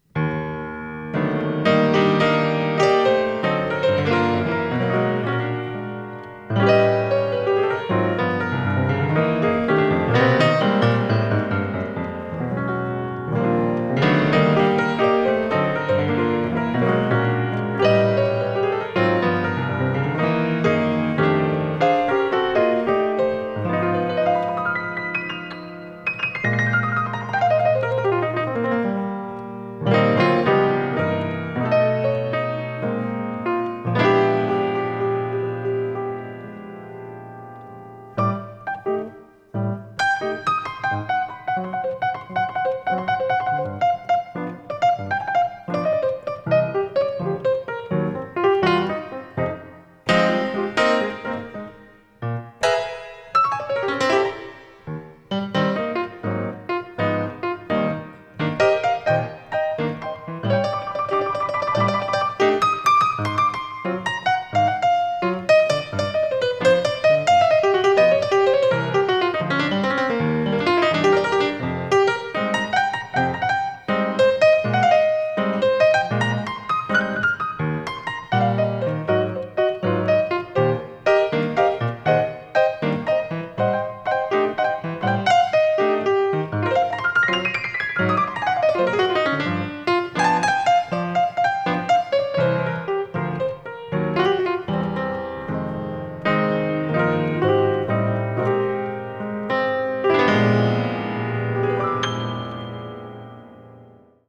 Choose a jazz note below.